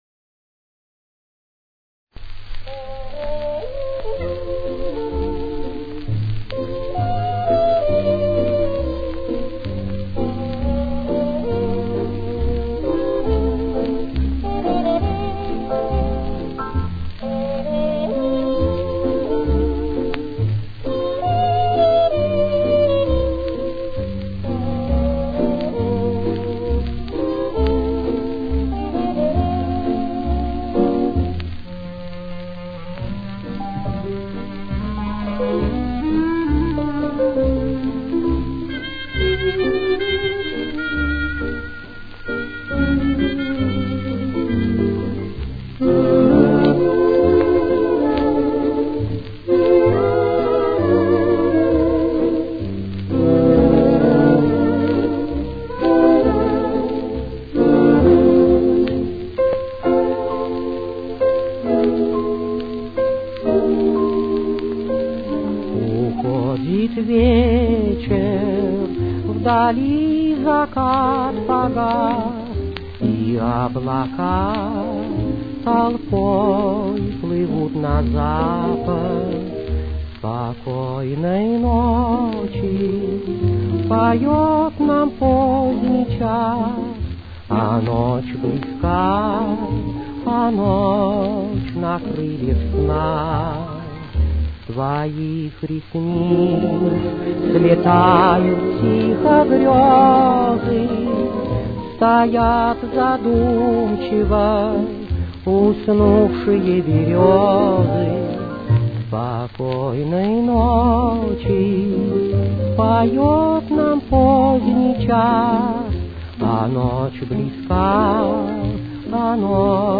с очень низким качеством (16 – 32 кБит/с)
Ми-бемоль мажор. Темп: 135.